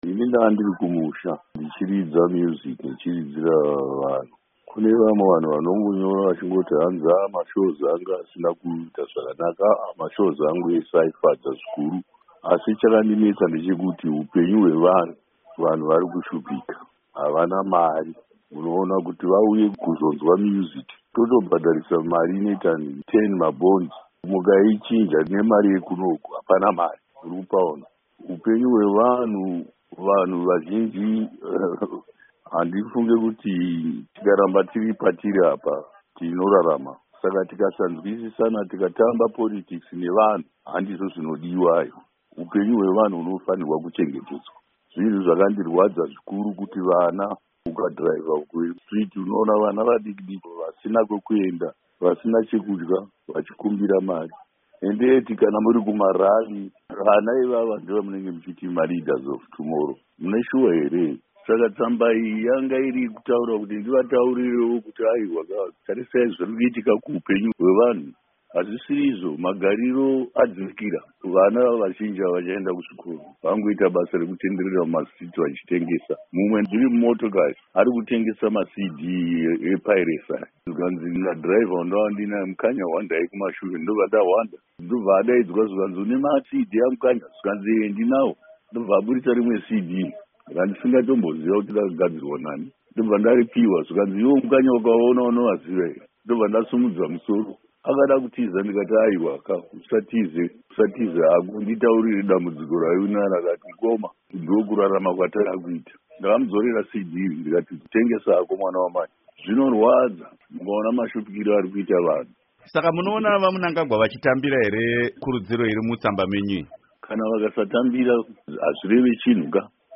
Hurukuro naDoctor Thomas Mapfumo